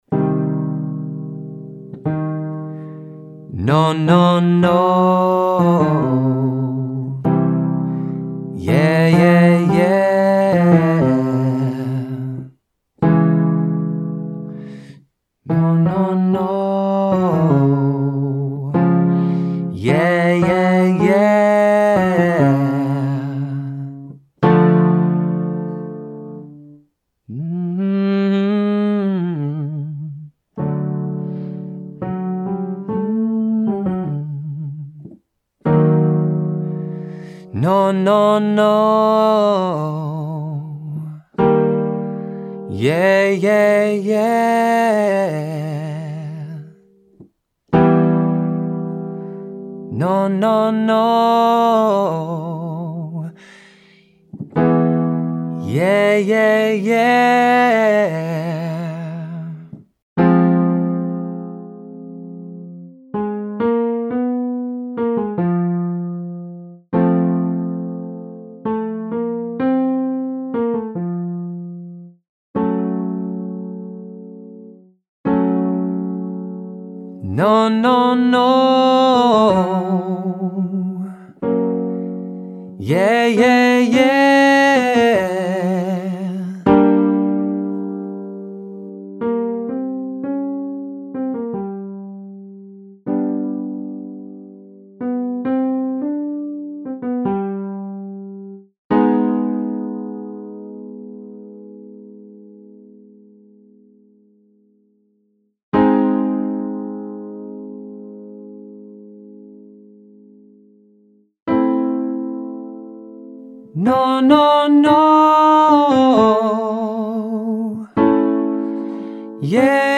Relaxed Riffs/Runs
Let’s polish these runs while keeping a rich tone. Start each phrase by accessing some vibrato on the sustained notes.